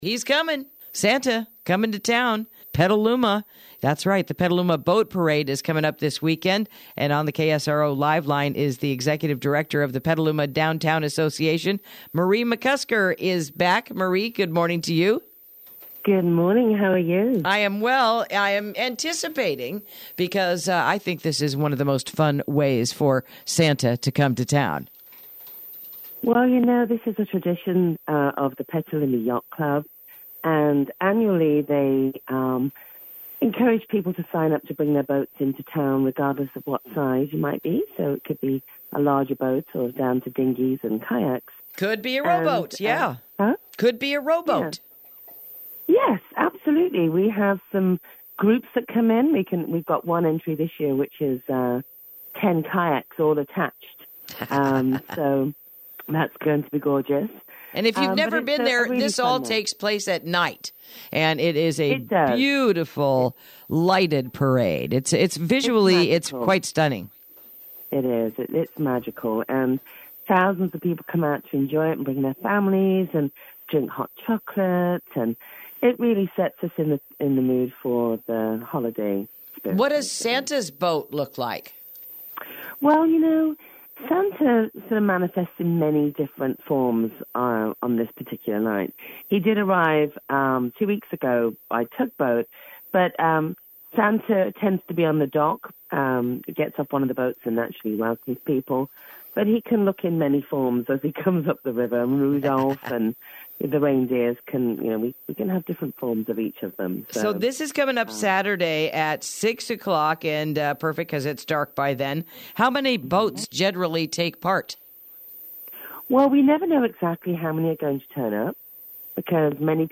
Interview: Petaluma Boat Parade this Weekend